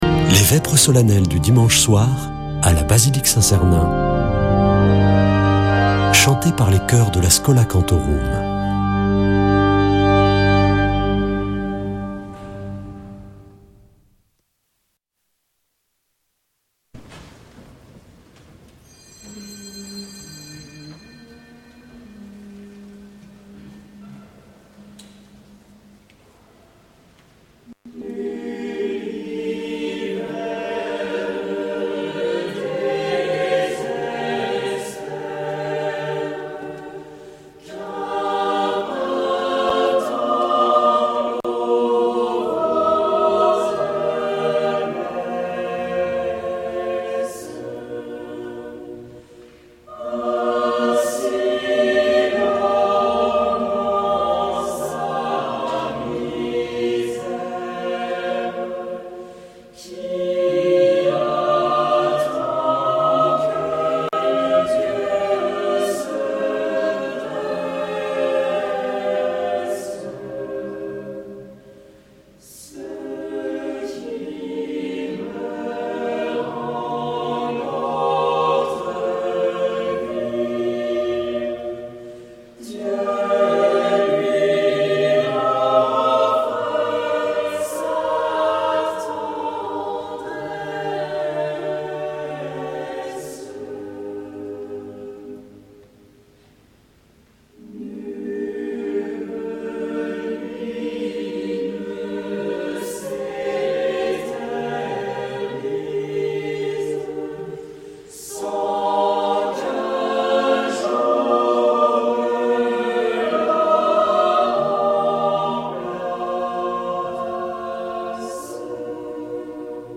Schola Saint Sernin Chanteurs